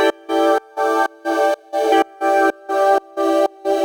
Index of /musicradar/sidechained-samples/125bpm
GnS_Pad-MiscB1:4_125-E.wav